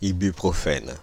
Ääntäminen
Ääntäminen Paris: IPA: [i.by.pʁɔ.fɛn] France (Île-de-France): IPA: /i.by.pʁɔ.fɛn/ Haettu sana löytyi näillä lähdekielillä: ranska Käännös Substantiivit 1. ibuprofen Suku: m .